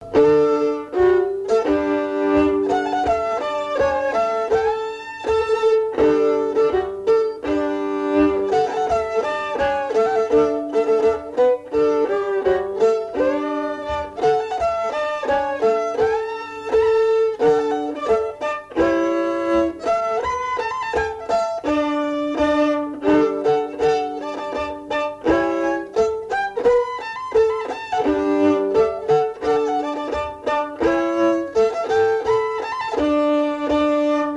Marche de noce
Usage d'après l'informateur circonstance : fiançaille, noce
Pièce musicale inédite